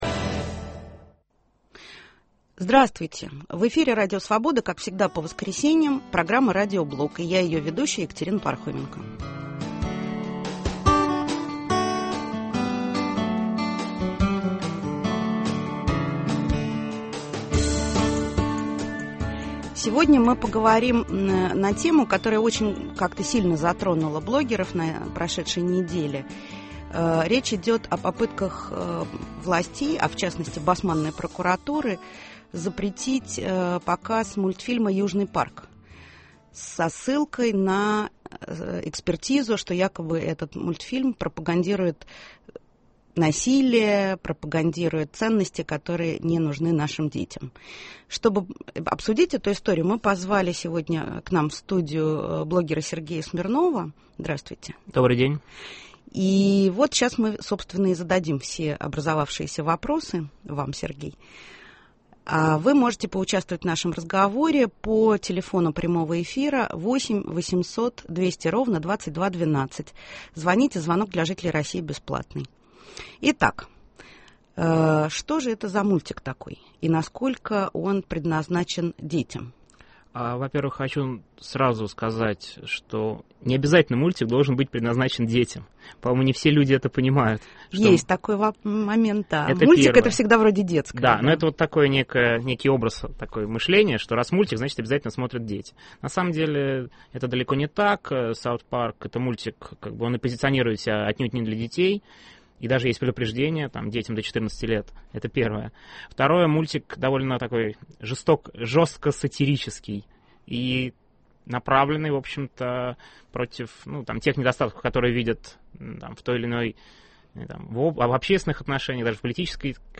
В воскресном Радиоблоге разговариваем о судьбе канала 2х2. Кто хочет его закрыть и почему, а кто пытается этому помешать. Гость в студии